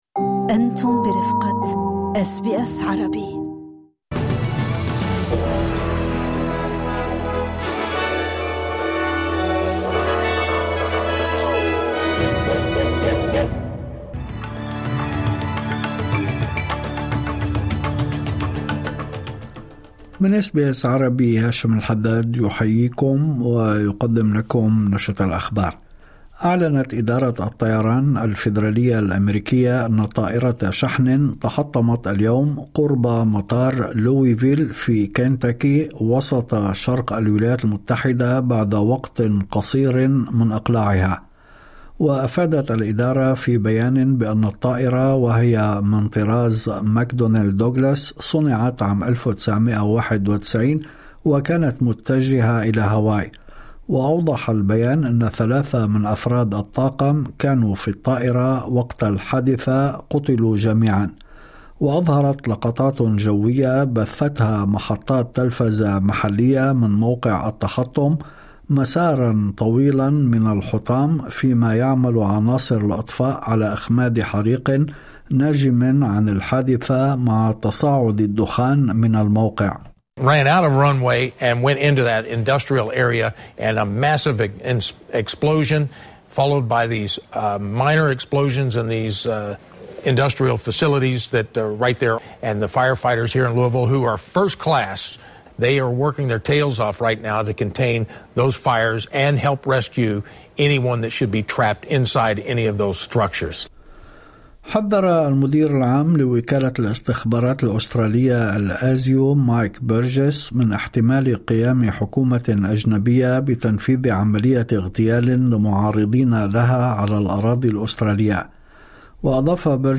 نشرة أخبار الظهيرة 05/11/2025